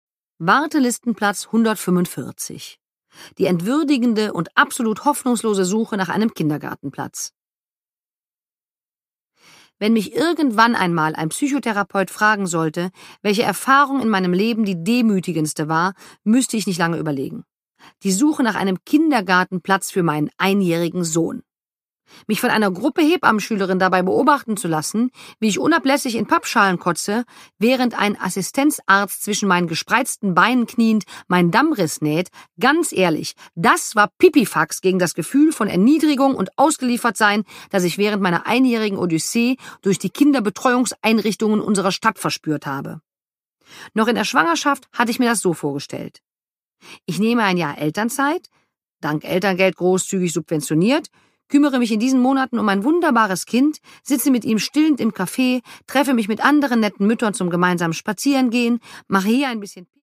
Produkttyp: Hörbuch-Download
Fassung: Autorisierte Lesefassung
Gelesen von: Mirja Boes